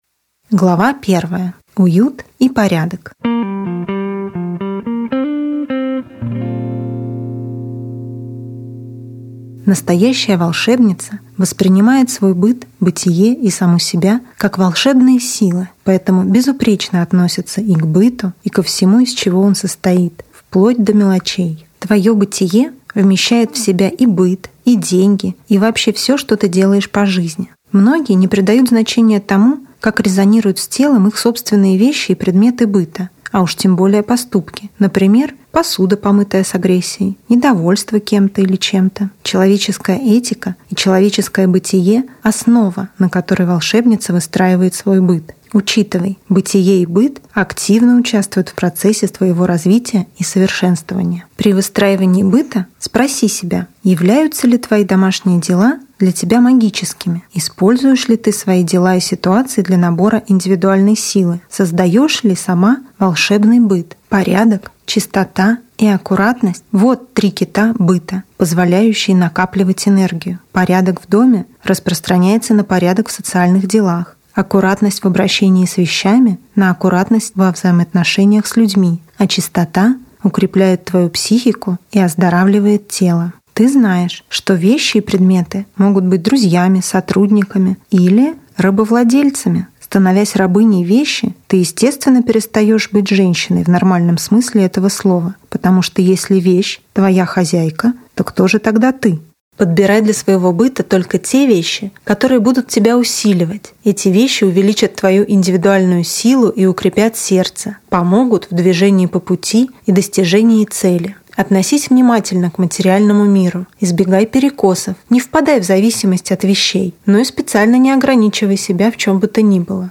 Аудиокнига Волшебный быт | Библиотека аудиокниг